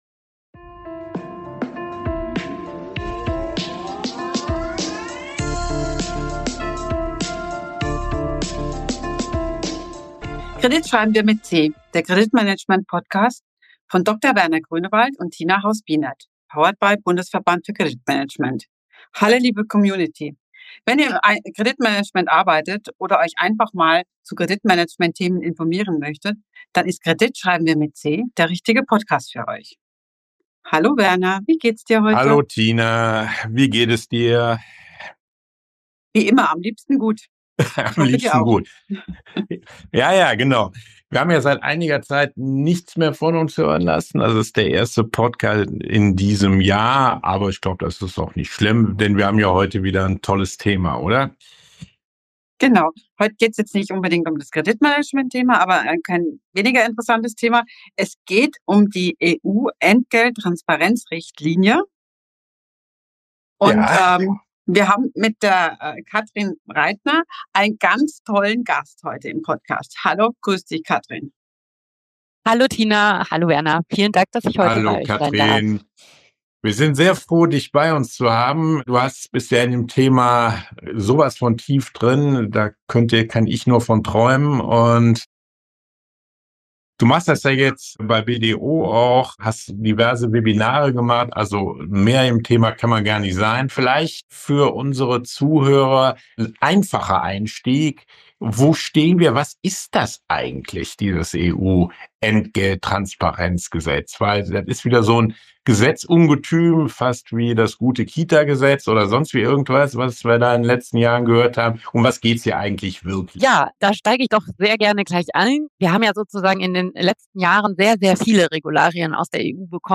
Ein praxisnahes Gespräch über EU-Recht, die Umsetzung im Recruiting in Deutschland und den Einfluß auf die Risikobewertung für alle Credit Manager*innen..